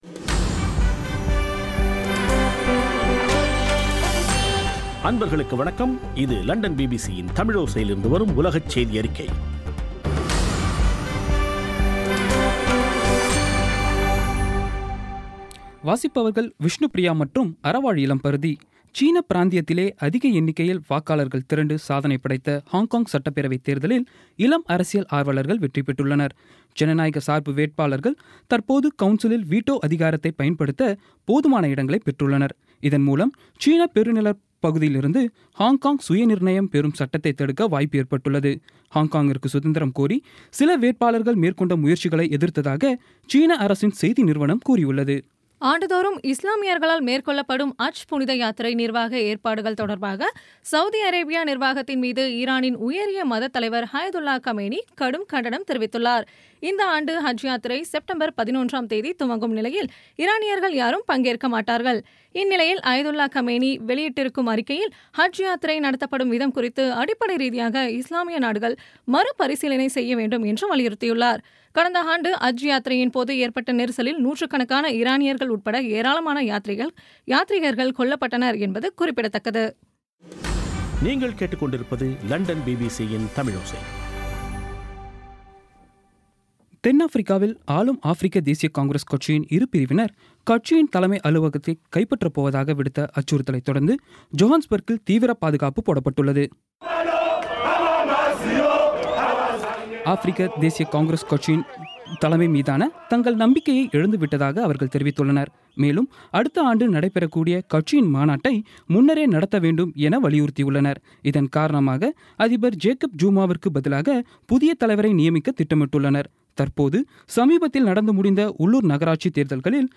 இன்றைய (செப்டம்பர் 5ம் தேதி) பிபிசி தமிழோசை செய்தியறிக்கை